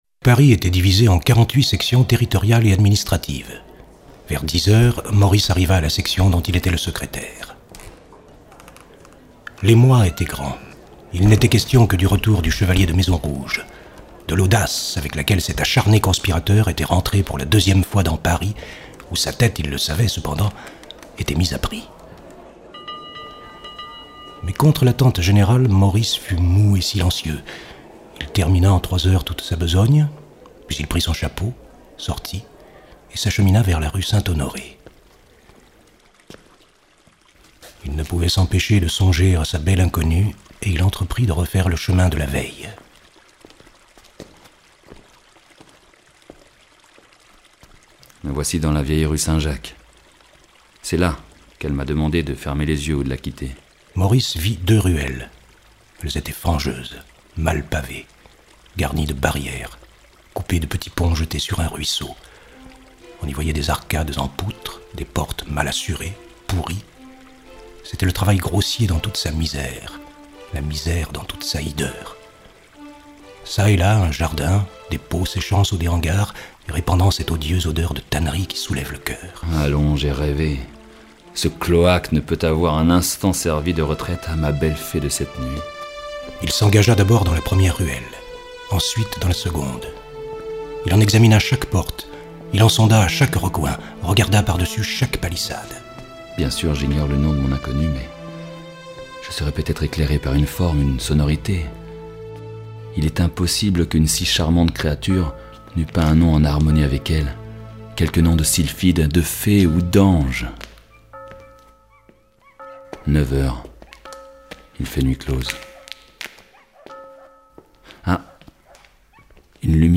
Diffusion distribution ebook et livre audio - Catalogue livres numériques
Adaptation, ambiance sonore et réalisation :